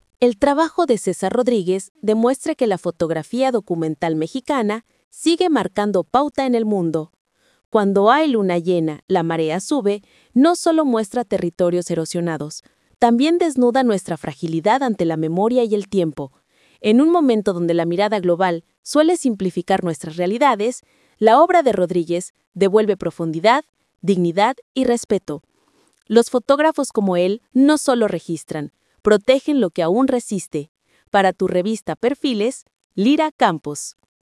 Entrevista en Radio Francia Internacional (RFI).
ESTA ES LA ENTREVISTA: